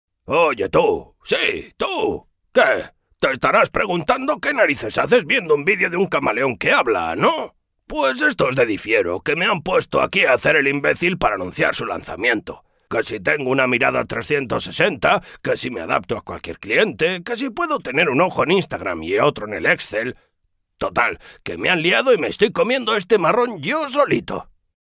some of my jobs as online castilian spanish and basque voiceover
06Personaje (Camaleón) CAST - Difiero
Demo-Camaleon-Difiero.mp3